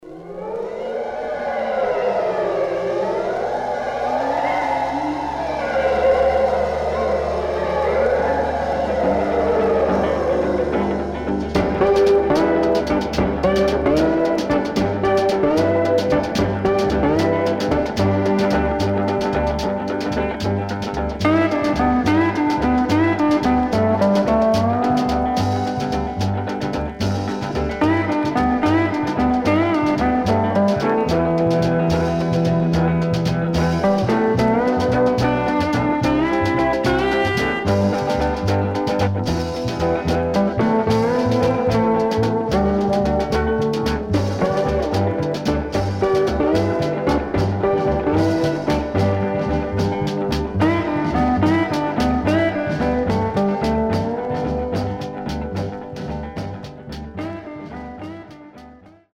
Instrumental Duo.155